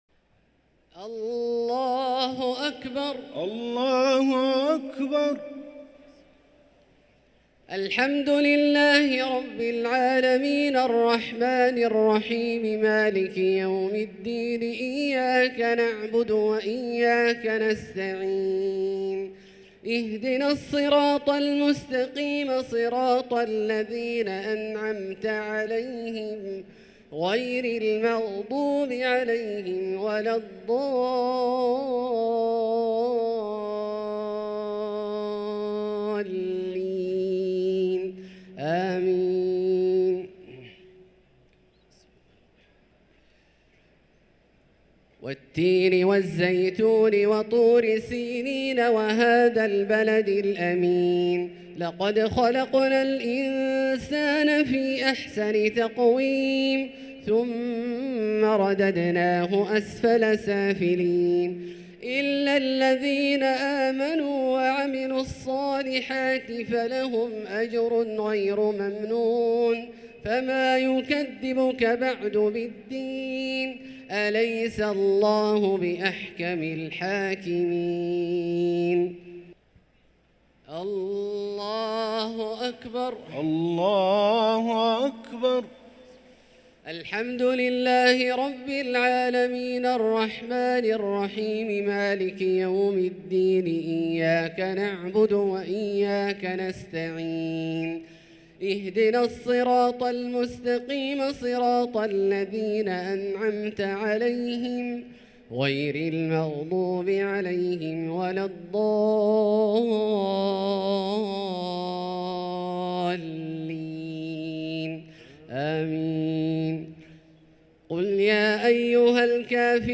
صلاة التراويح ليلة 7 رمضان 1444 للقارئ عبدالله الجهني - الشفع والوتر - صلاة التراويح